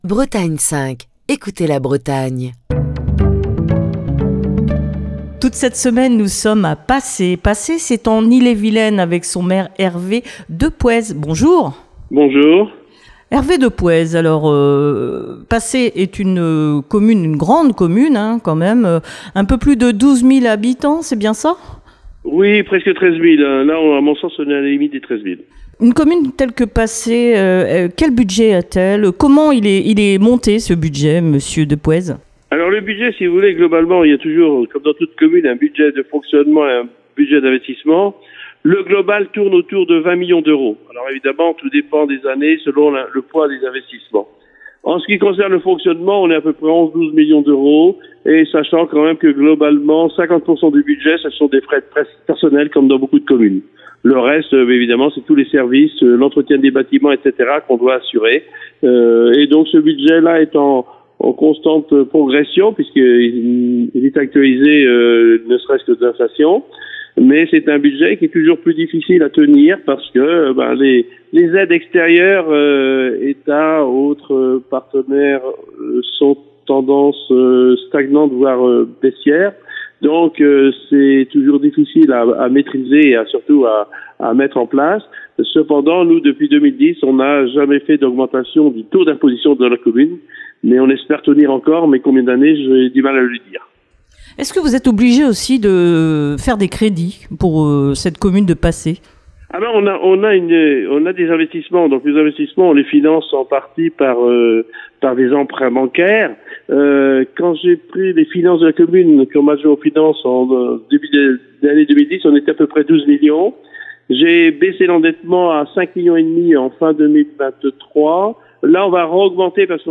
s'entretient au téléphone